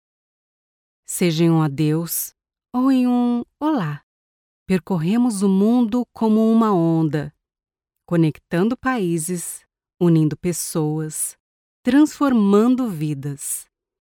Livres audio
Her voice is pleasant and versatile and can help you create a unique and captivating listening experience for your audience.
I have a professional home studio with all the bells and whistles.